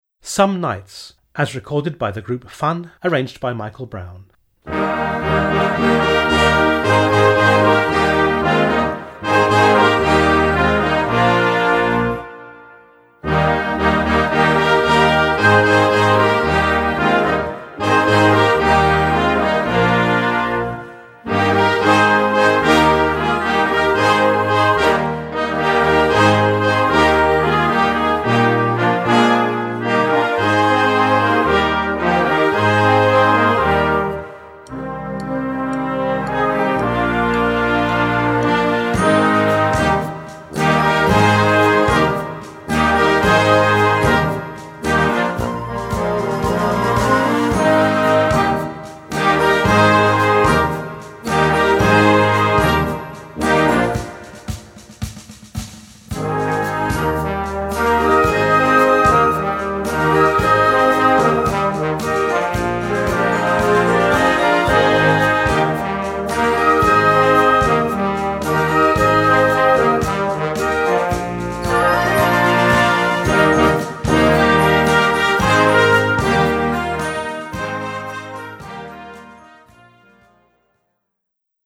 Gattung: Moderner Einzeltitel Jugendblasorchester
Besetzung: Blasorchester